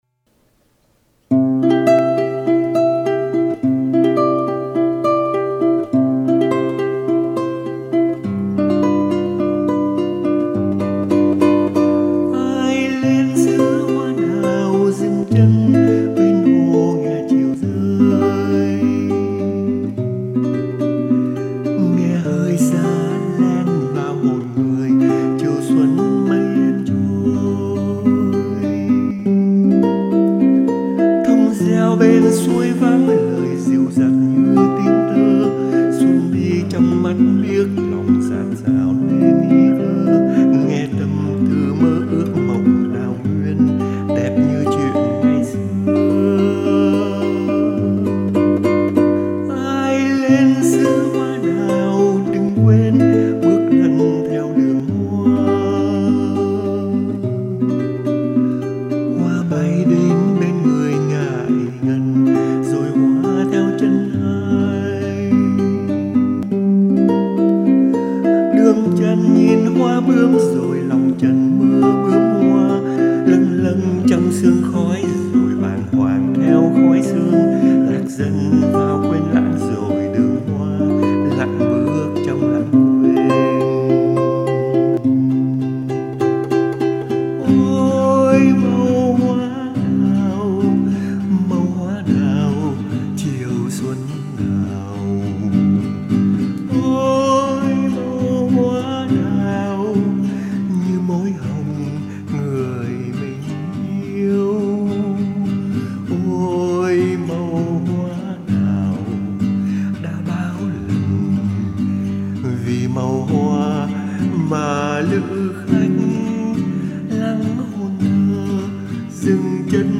đàn và hát.